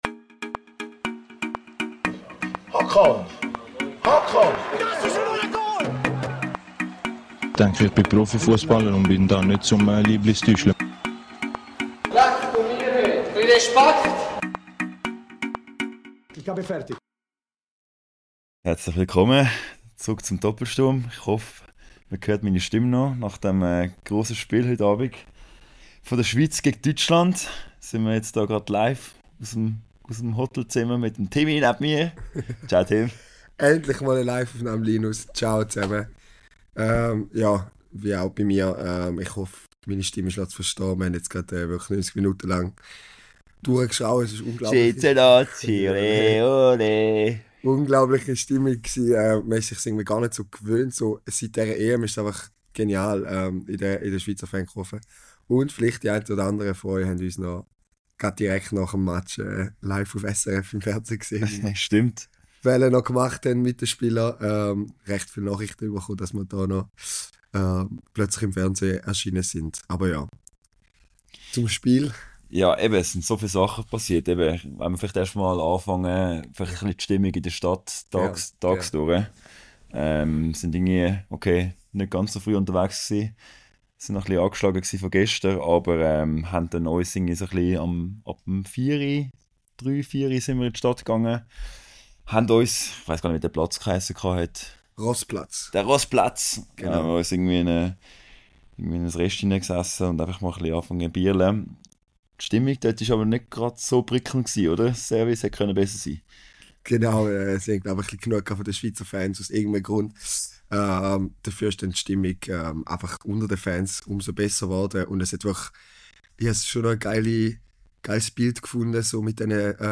#49 Live aus dem Waldstadion in Frankfurt ~ Doppelsturm Podcast
In Episode 49 von "Doppelsturm" melden wir uns live nach dem Spiel zwischen der Schweiz und Deutschland aus dem legendären Waldstadion in Frankfurt! Erlebt das einzigartige Erlebnis und hört unsere exklusiven Eindrücke direkt nach dem Spiel.